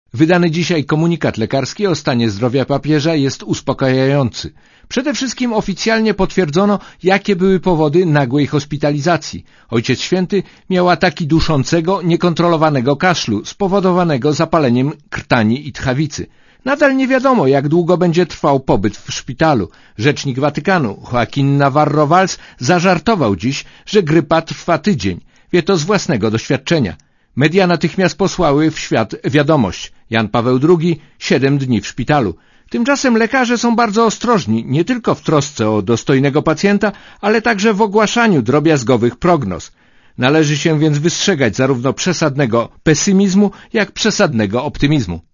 Relacja rzymskiego korespondenta Radia ZET